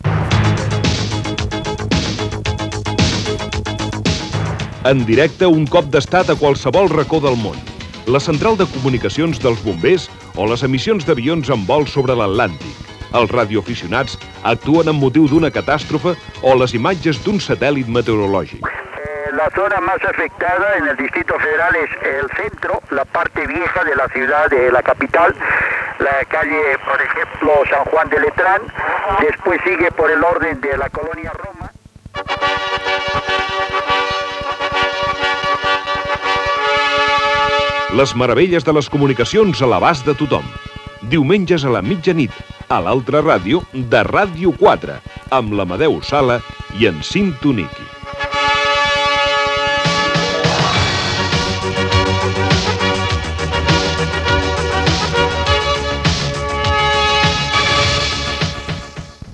Indicatiu del programa de la temporada 1986-87.